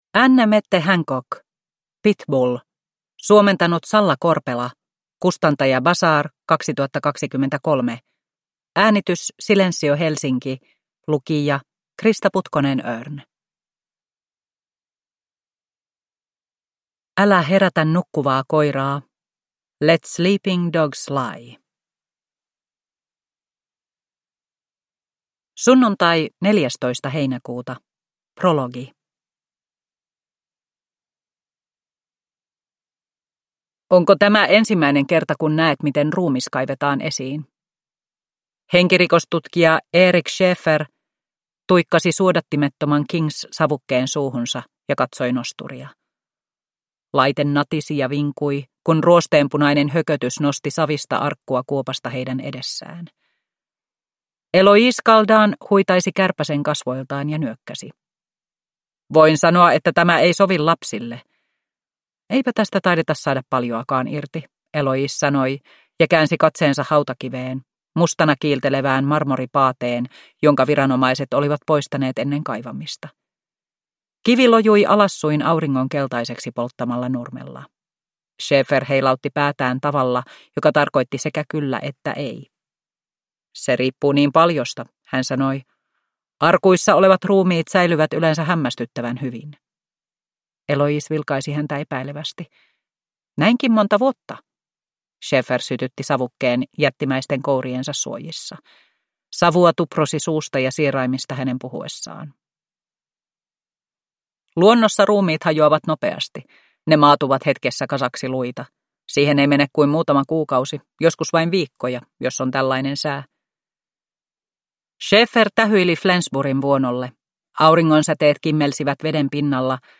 Pitbull – Ljudbok – Laddas ner